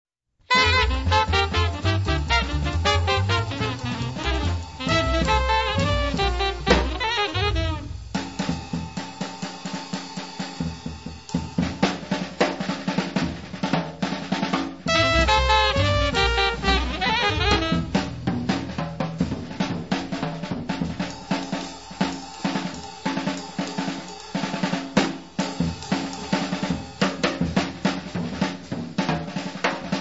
batteria
contrabbasso
tromba
• free jazz
• jazz moderno